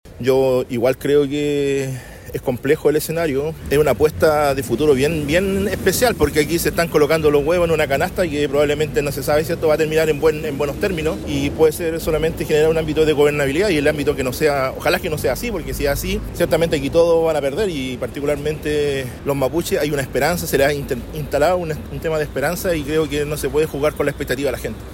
Durante este sábado en el Liceo Bicentenario de Temuco comenzaron los diálogos interculturales con la participación de autoridades de Gobierno, representantes y dirigentes mapuches, entre otros.